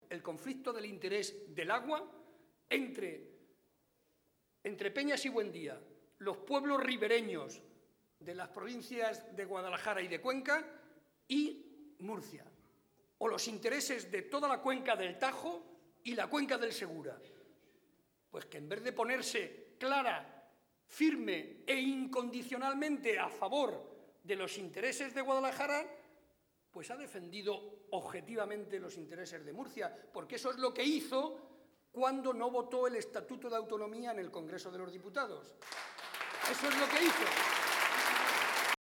Momento del acto público del PSOE celebrado en Alovera.